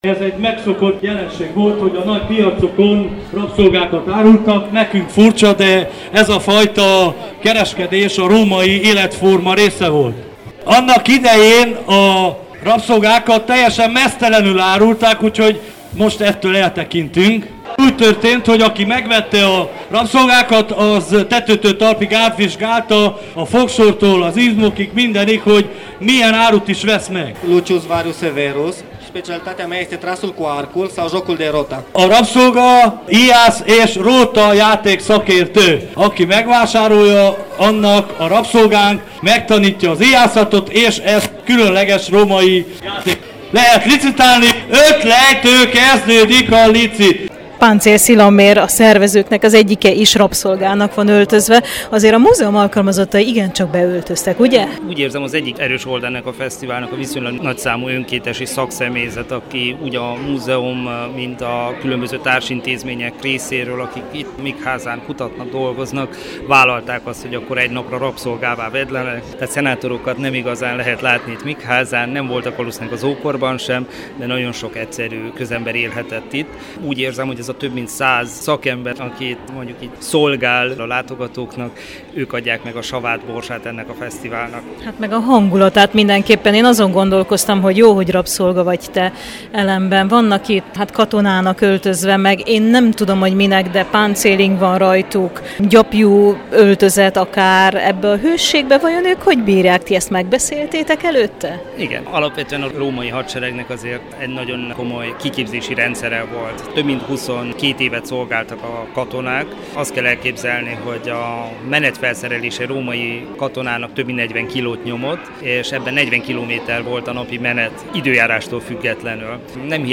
A múzeum alkalmazottai is beöltöztek, és a parádék mellett, az egykori rabszolgavásárokat idéző bemutató is volt.